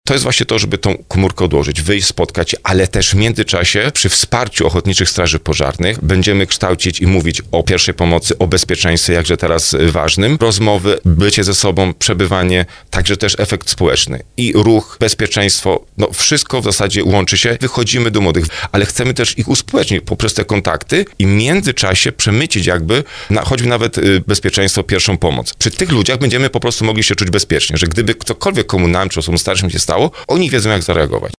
Wójt Marcin Kiwior, który jest także propagatorem aktywnego wypoczynku podkreśla, że warto skrócić czas spędzony na surfowaniu po Internecie.